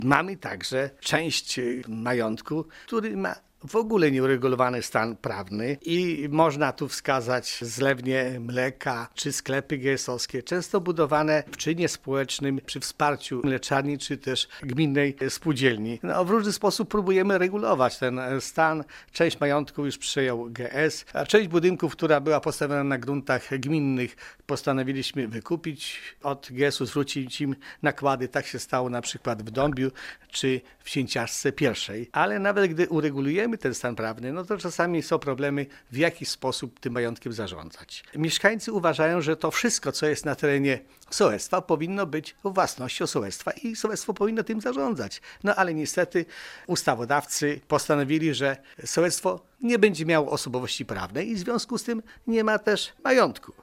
„Minęło 20 lat i problem nadal nie jest rozwiązany” - mówi zastępca wójta gminy Łuków Wiktor Osik: